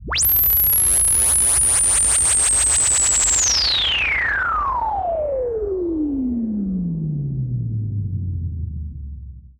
Roland E Noise 11.wav